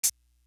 Bring You Light Hat.wav